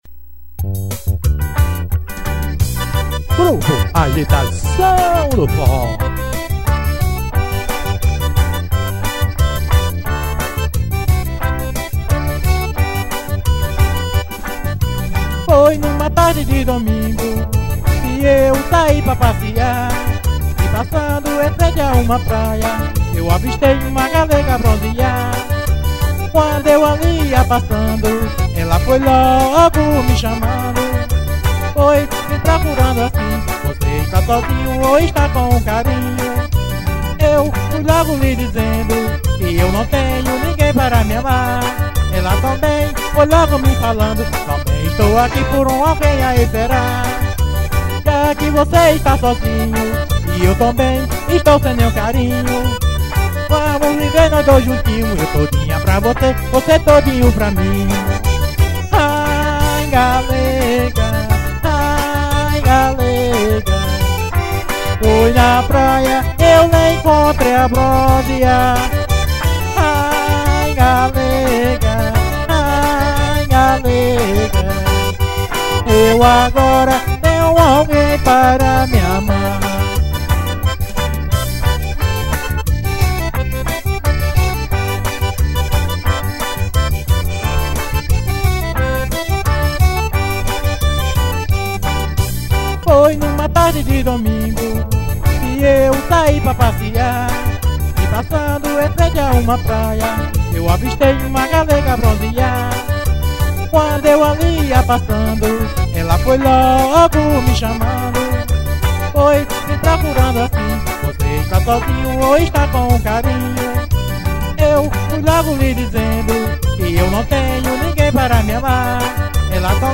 chote.